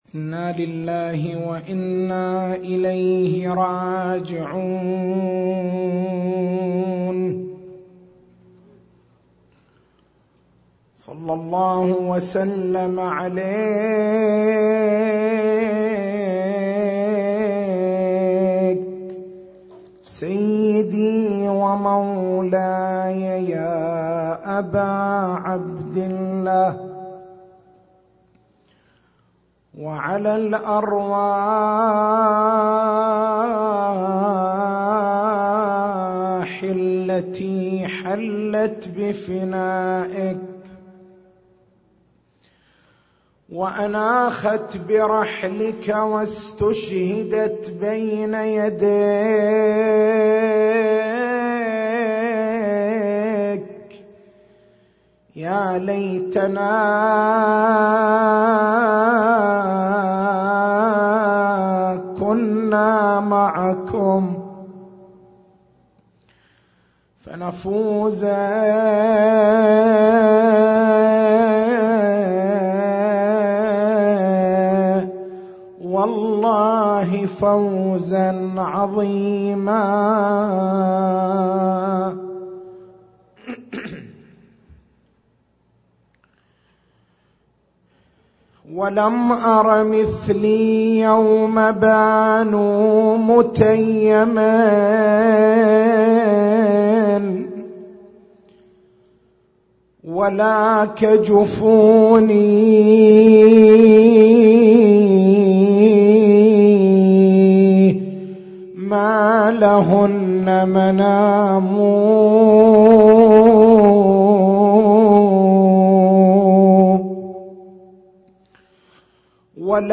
تاريخ المحاضرة: 03/01/1438